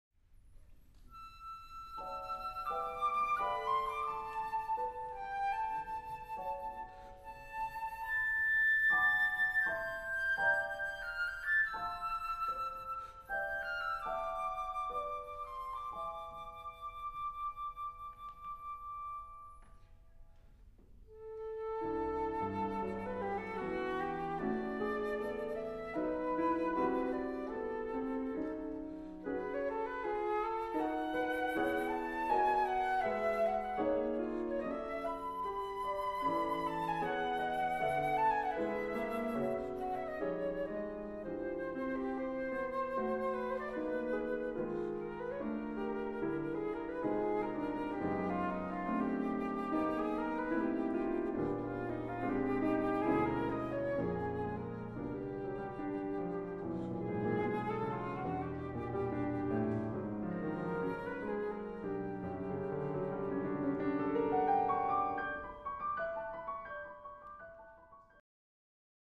for Flute and Piano
・於）'03年5/29東京オペラシティ・リサイタルホール
フルート
ピアノ